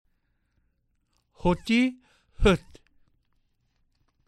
I.  Listen to the difference between o and ö.  This difference only shows up in the short forms.